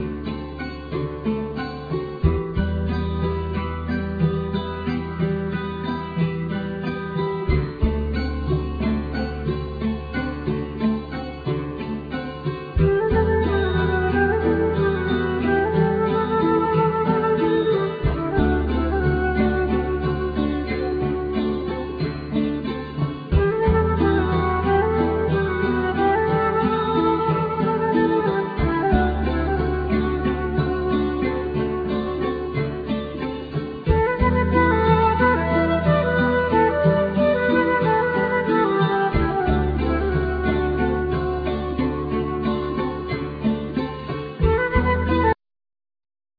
Accordion
Guitar
Mandolin
Cello